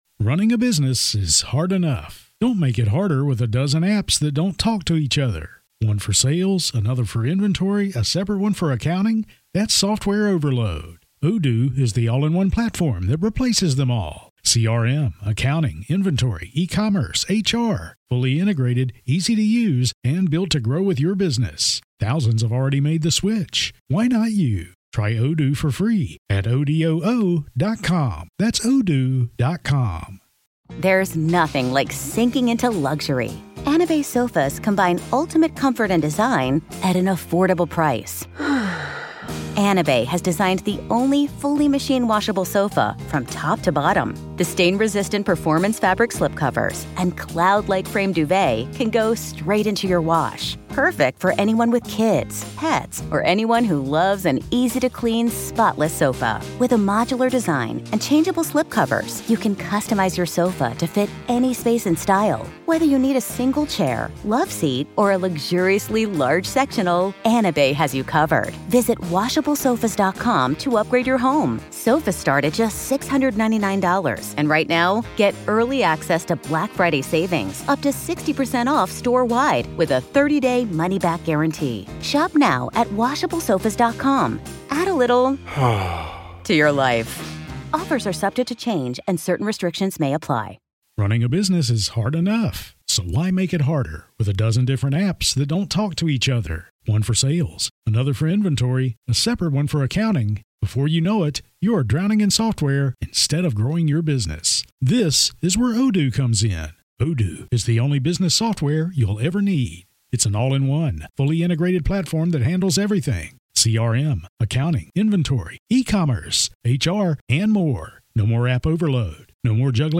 Listen as the prosecution and defense present their cases, with firsthand testimonies and critical evidence painting a gripping narrative of the tragic day. Discover the trial’s key moments and turning points and gain in-depth insights into the legal strategies and consequences.